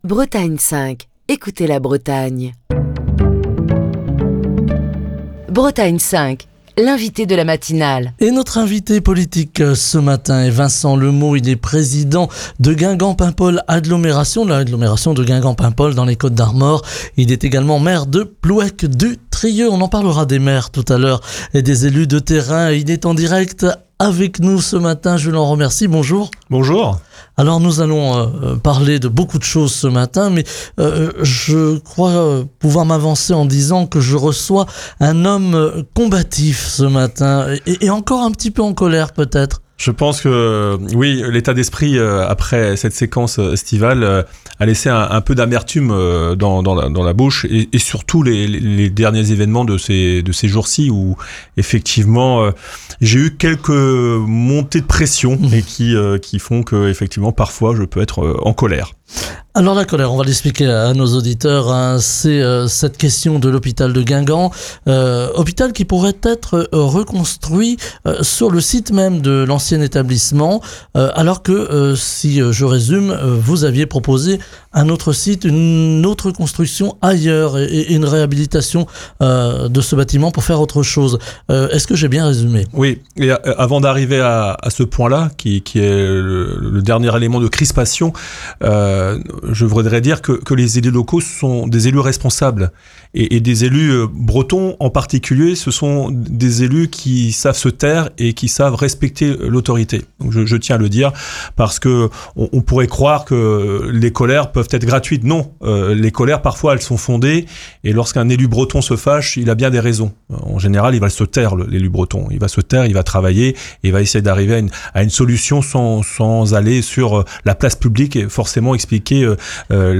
Ce matin, Vincent Le Meaux, président de Guingamp-Paimpol Agglomération, maire de Plouëc-du-Trieux, revient sur le feuilleton de la sauvegarde de l'hôpital de Guingamp, au micro de la matinale de Bretagne 5. Il évoque également les arrêtés municipaux pris au mois de juin par plusieurs maires des Côtes d'Armor pour défendre l'accès aux soins et mettre l’État face à ses responsabilités en matière de santé.